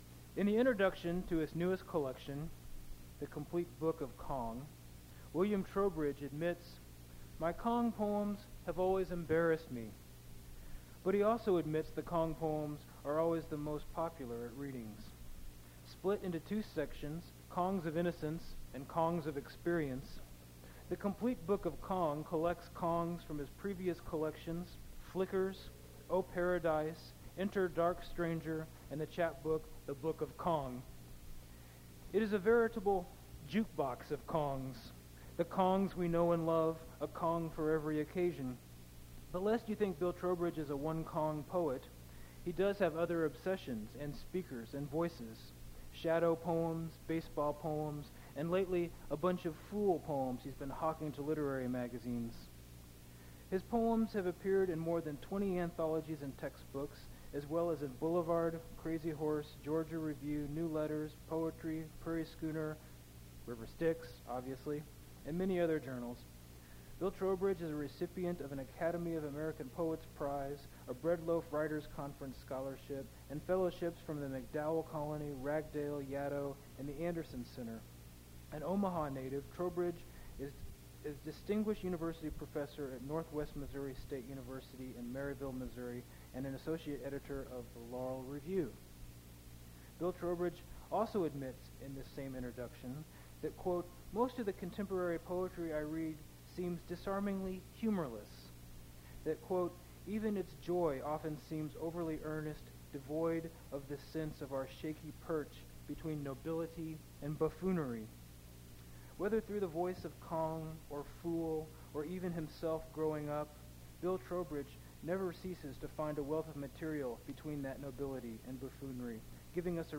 Poetry reading
mp3 edited access file was created from unedited access file which was sourced from preservation WAV file that was generated from original audio cassette.
Cut irrelevant remarks at beginning; audio is relatively quiet; recording cuts out in the middle of last poem